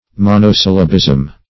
Search Result for " monosyllabism" : The Collaborative International Dictionary of English v.0.48: Monosyllabism \Mon`o*syl"la*bism\, n. The state of consisting of monosyllables, or having a monosyllabic form; frequent occurrence of monosyllables.
monosyllabism.mp3